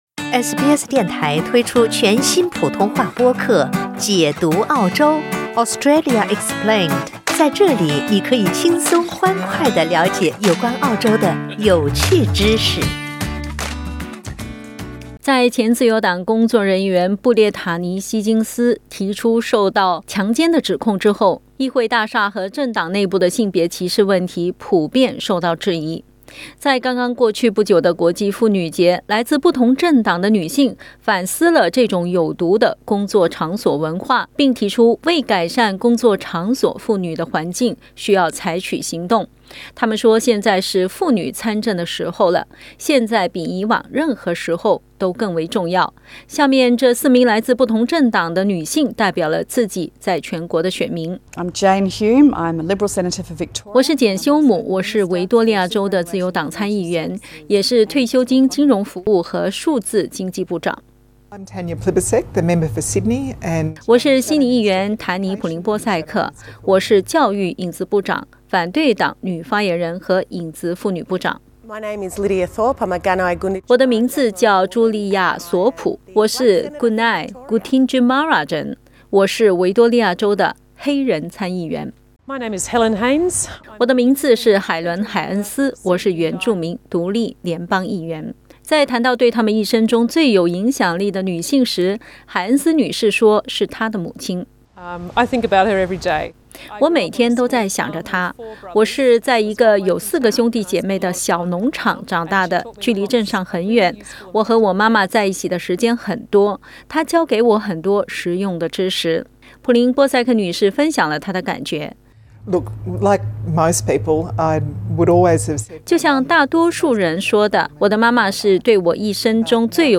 （请听报道） 澳大利亚人必须与他人保持至少1.5米的社交距离，请查看您所在州或领地的最新社交限制措施。